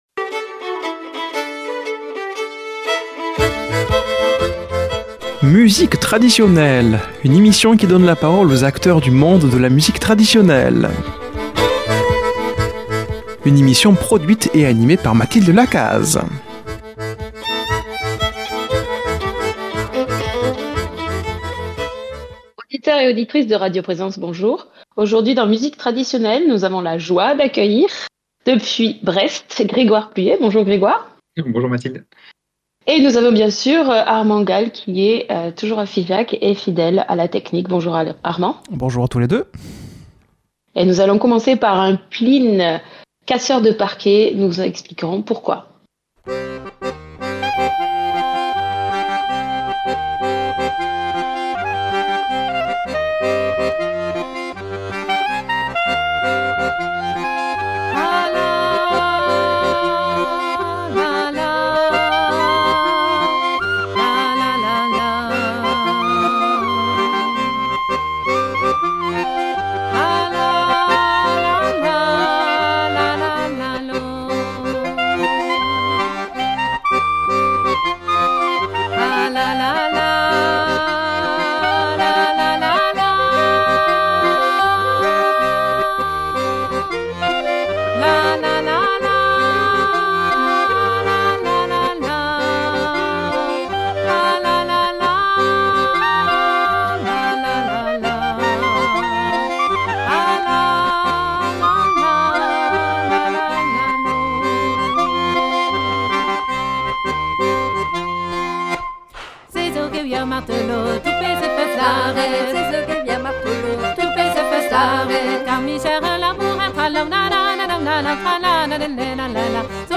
Musique Traditionnelle